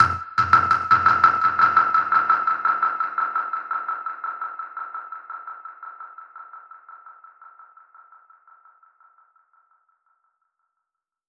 DPFX_PercHit_B_85-03.wav